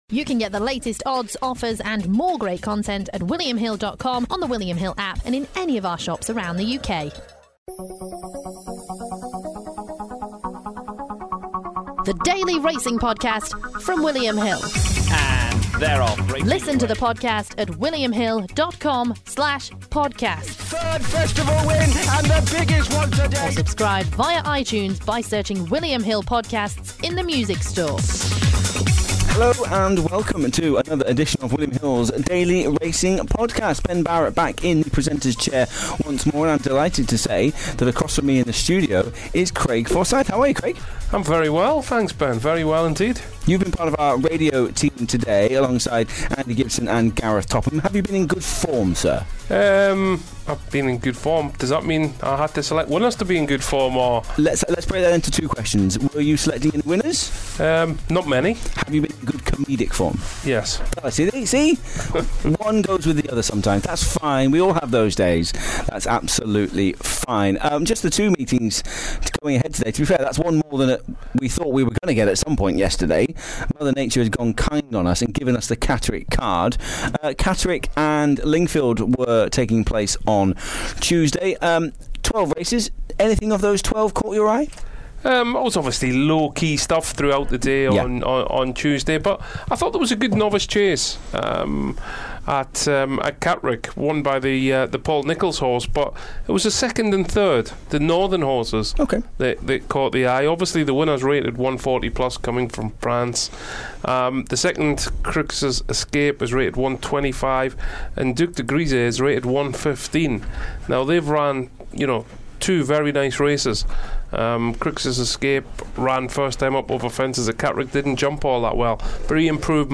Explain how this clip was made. We chat about the racing from Catterick and Lingfield on Tuesday, preview a couple of races on Wednesday from Mussleburgh and then, we bring you a new feature recorded on Monday as part of the Racing Radio program.